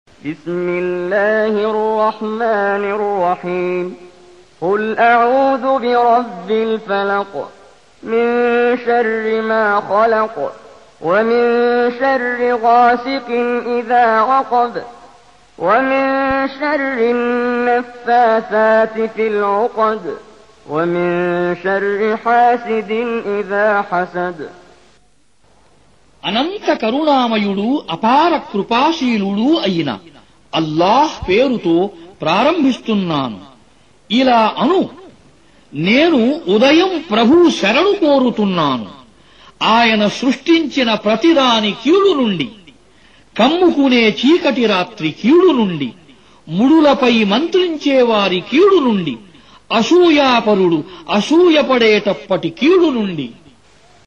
Audio Quran Tarjuman Translation Recitation